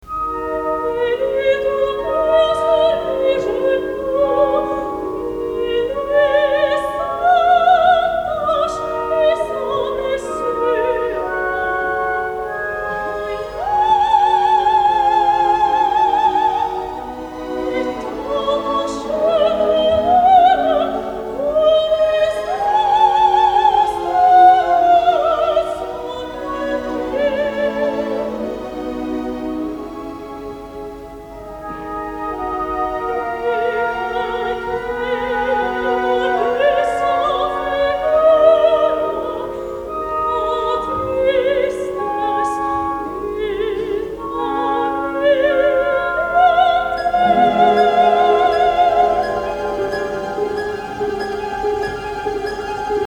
Oratorium
(Georges Pretre,Wiener Philharmoniker)